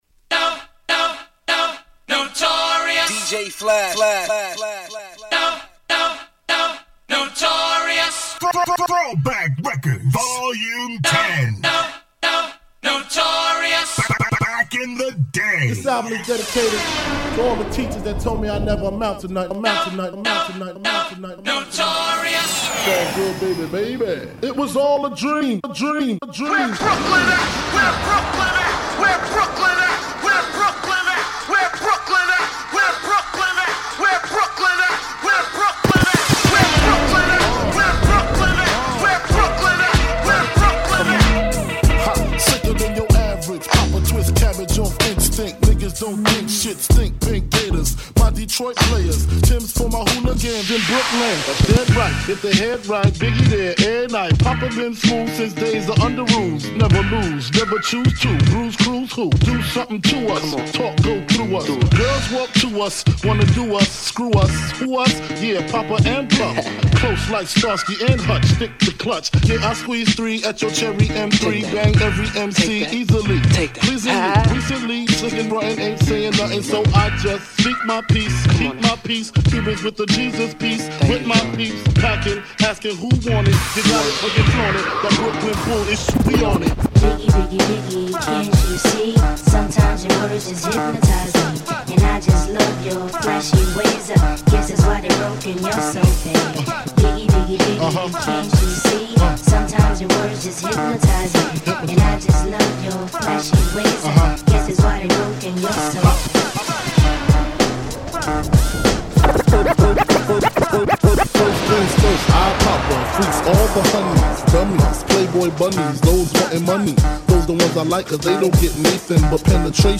Had to the tribute mix